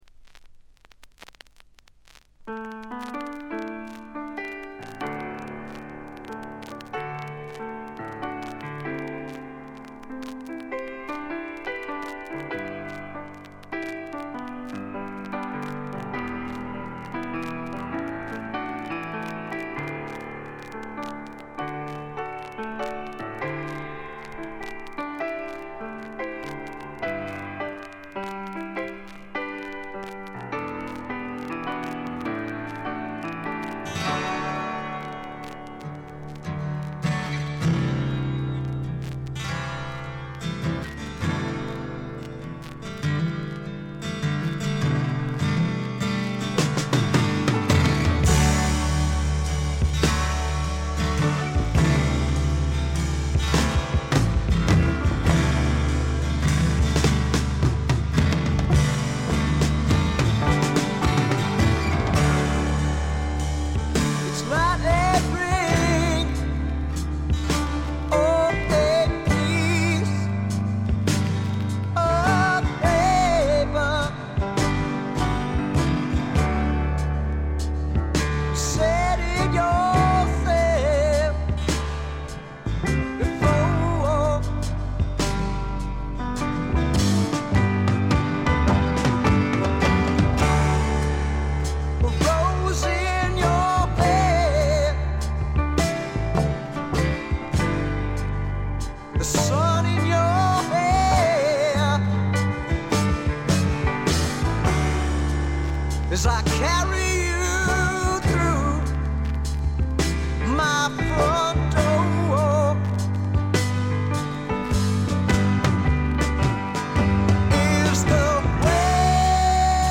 見た目に反して、全体にバックグラウンドノイズ、チリプチが多め。
基本は英国的としか言いようのない重厚で深い陰影のある哀愁のフォークロックです。
試聴曲は現品からの取り込み音源です。